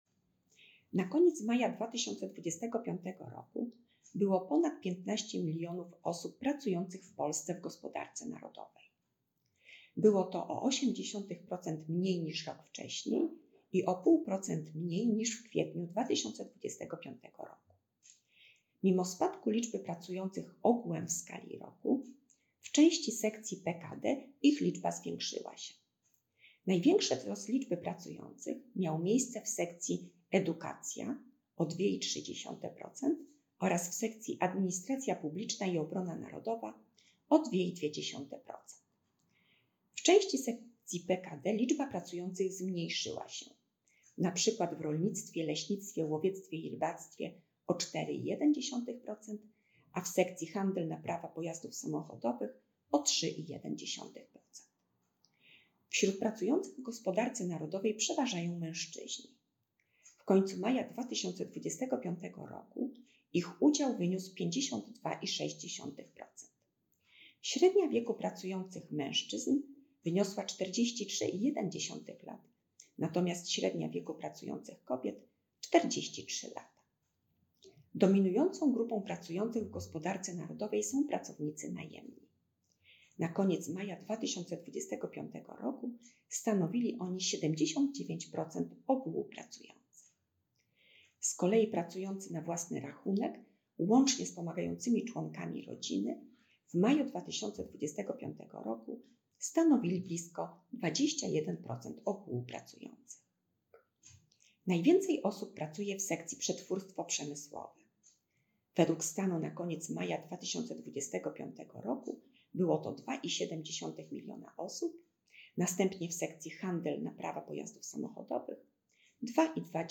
Ponad 15 milionów pracujących w gospodarce narodowej. Wypowiedź Wiesławy Gierańczyk, Dyrektor Urzędu Statystycznego w Bydgoszczy w formacie MP3